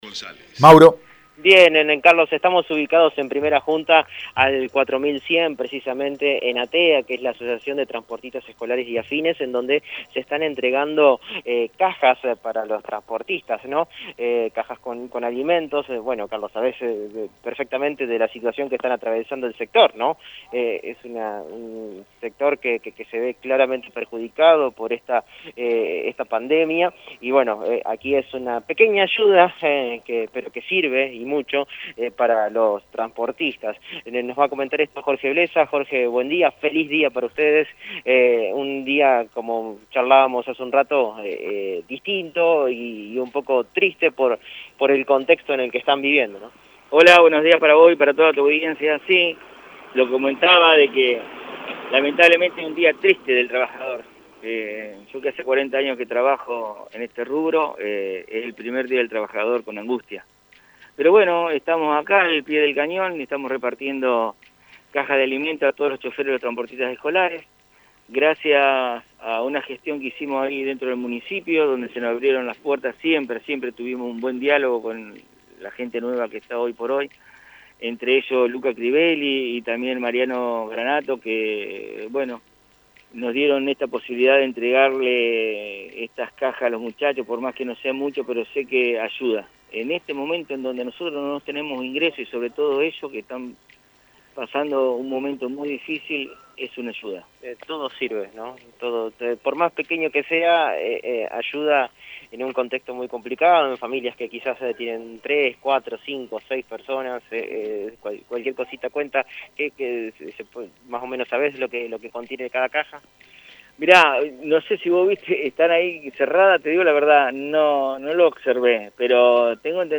en diálogo con Radio EME.